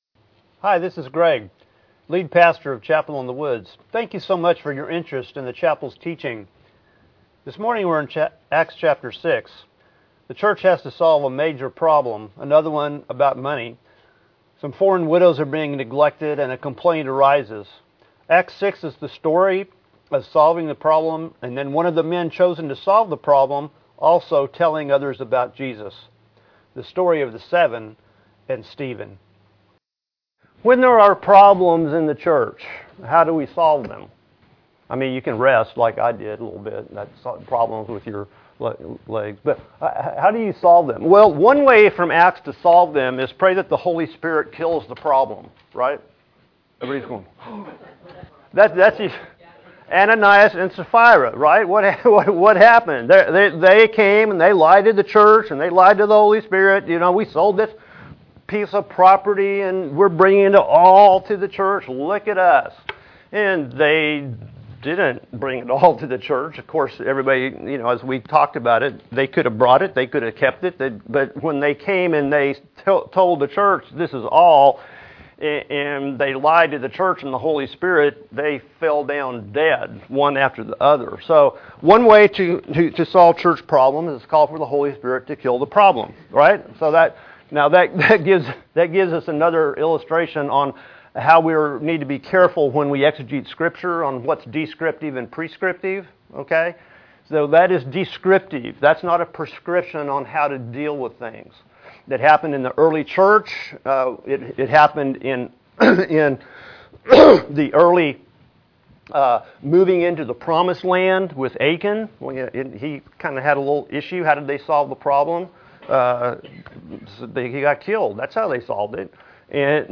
Feb 25, 2018 Acts 6 Another problem solved MP3 SUBSCRIBE on iTunes(Podcast) Notes Discussion Sermons in this Series Foreign widows in the church were not being cared for so the church solves the problem by choosing people who can do so.